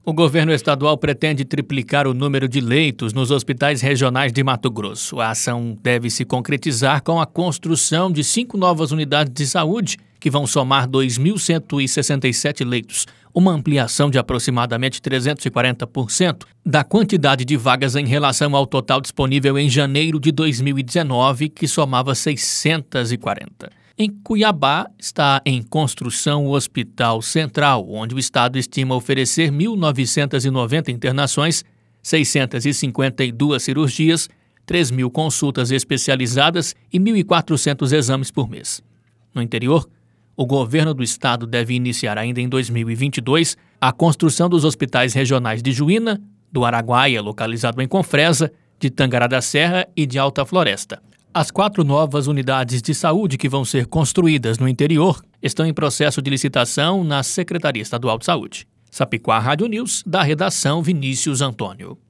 Boletins de MT 03 mar, 2022